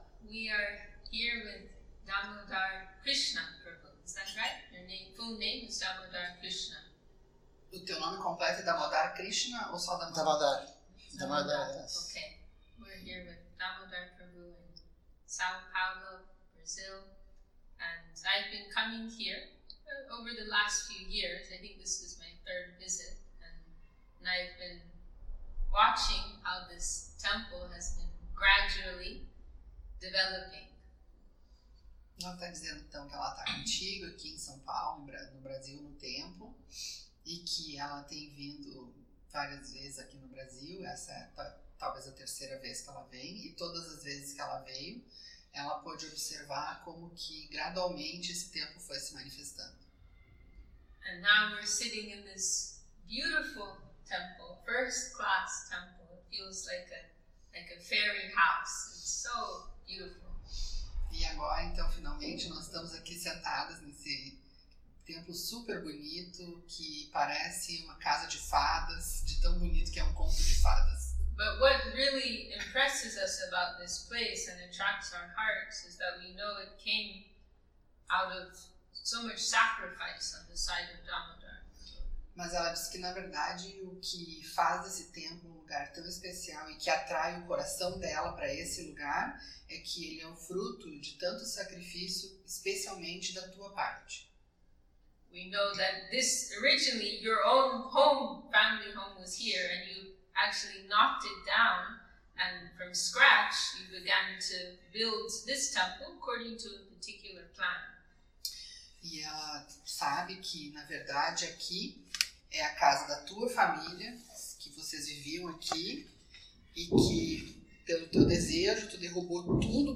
Portuguese and English with translation